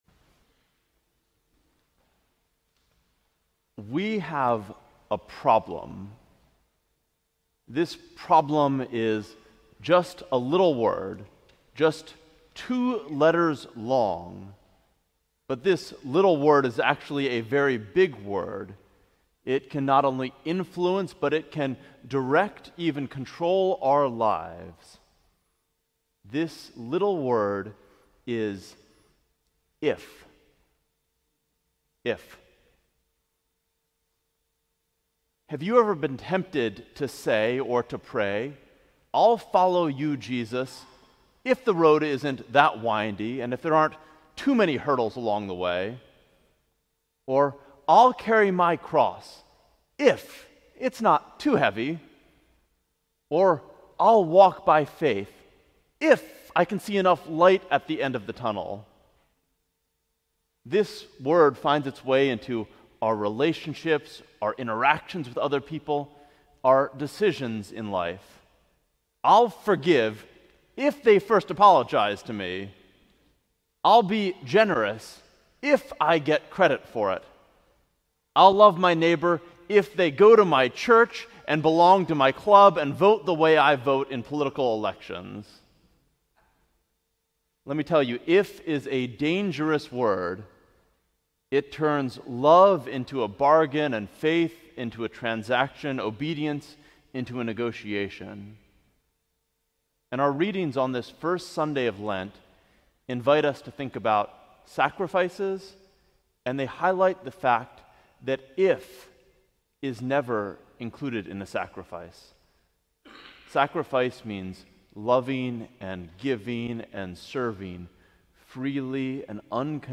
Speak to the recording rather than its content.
The First Sunday in Lent. Question: Is there a time when you made a sacrifice?